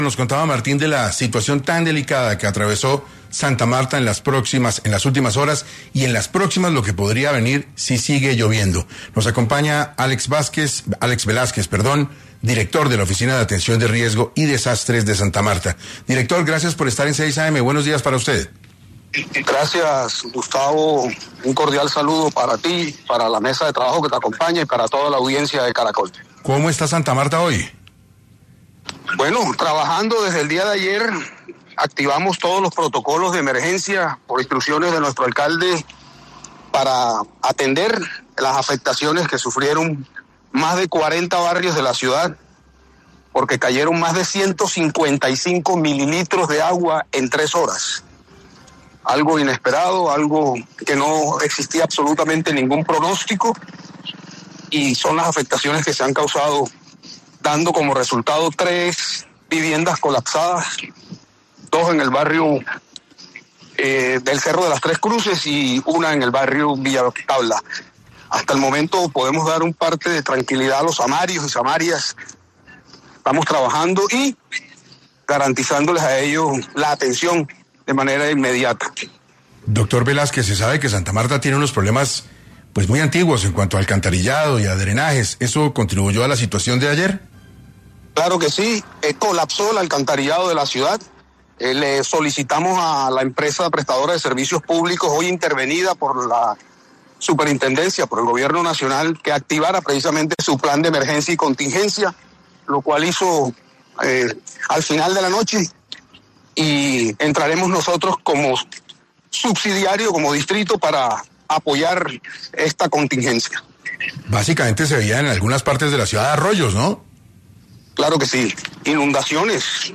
En este orden de ideas, Álex Velásquez, director de la Oficina de Atención de Riesgo y Desastres de Santa Marta, pasó por los micrófonos de 6AM para hablar de las principales afectaciones de esta ciudad.